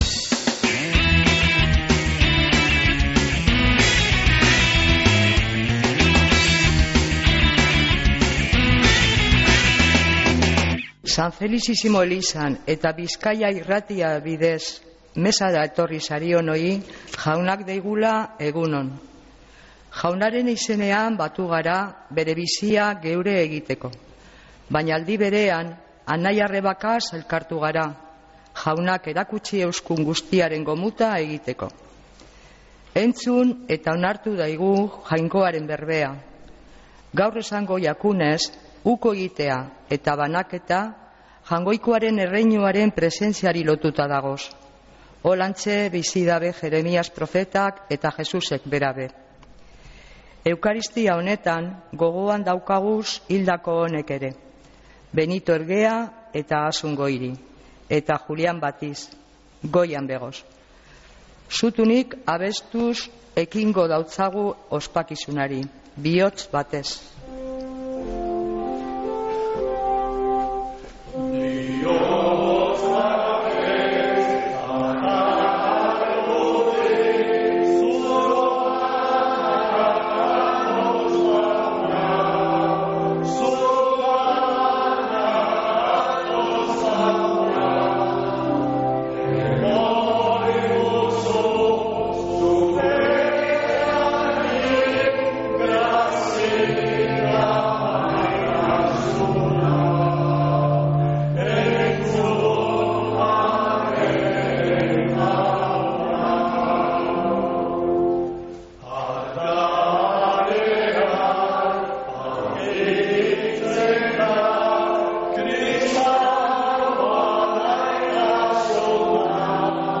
Mezea (25-08-17)